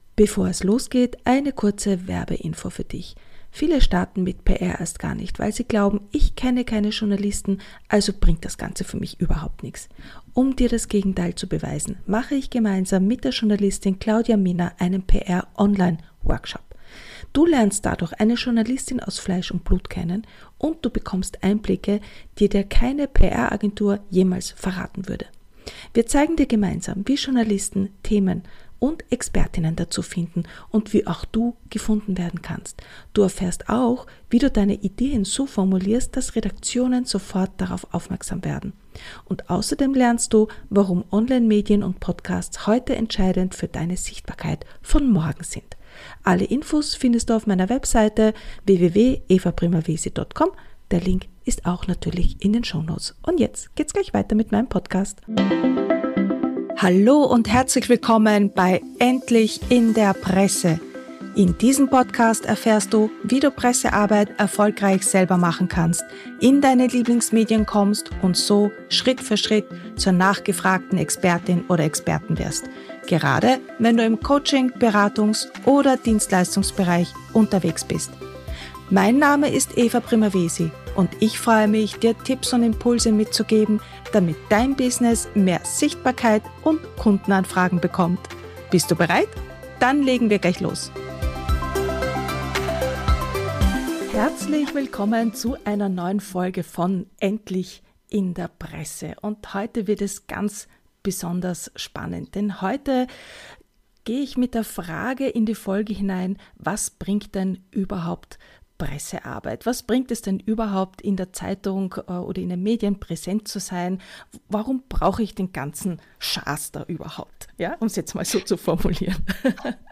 Wenn du die Abkürzung lernen und die typischen Fehler vermeiden möchtest, um als Expert:in sichtbar zu werden, dann hör dir dieses inspirierende Gespräch unbedingt an.